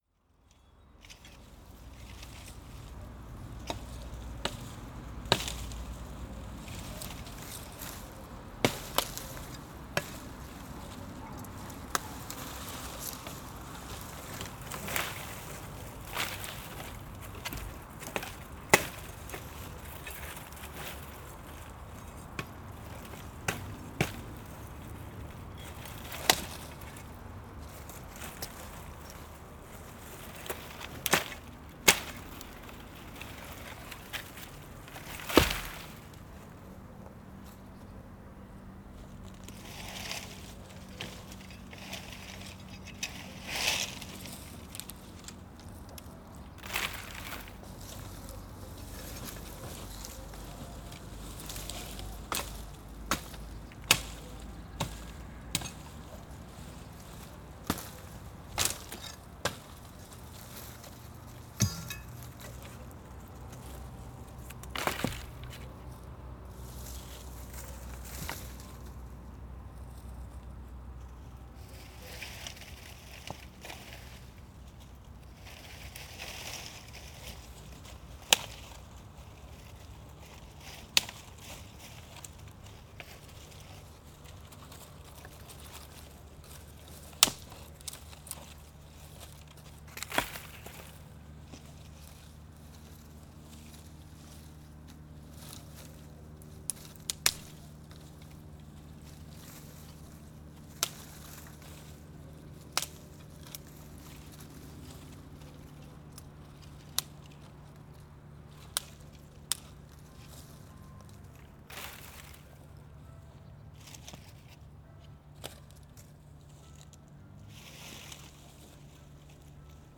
Paisagem sonora de recolha e corte de madeira em baldio junto ao Parque Desportivo / Recinto de Festas de Vila Nova do Campo, Campo a 3 Março 2016.
NODAR.00560 – Campo: Recolha e corte de madeira em baldio junto ao Parque Desportivo / Recinto de Festas de Vila Nova do Campo